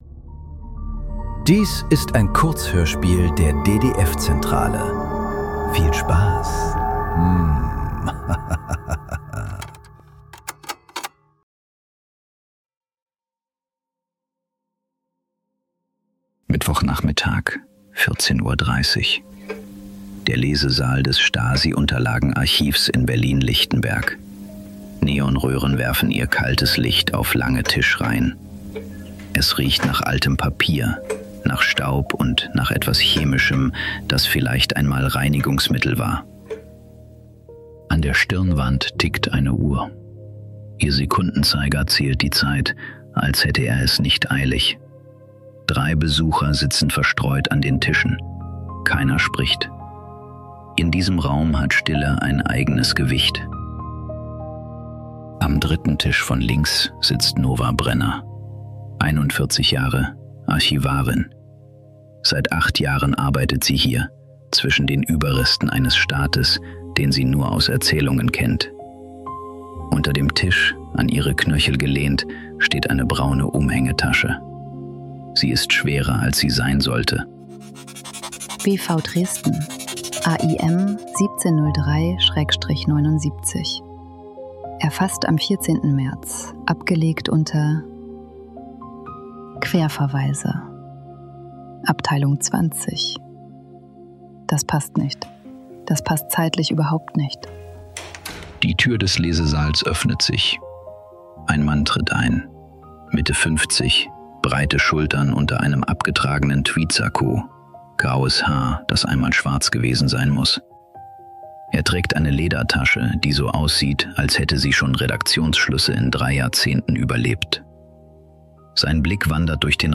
Die Akte Lerche ~ Nachklang. Kurzhörspiele.